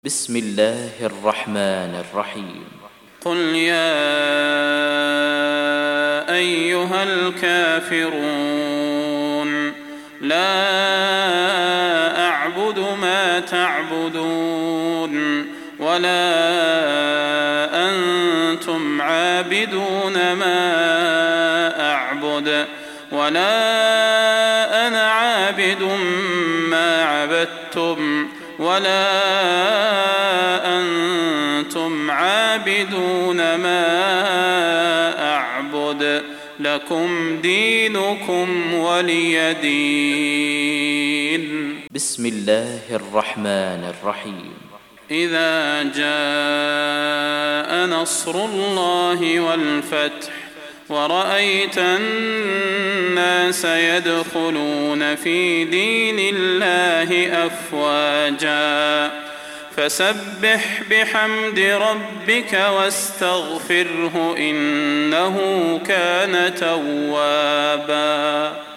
فروض مغرب البدير 1420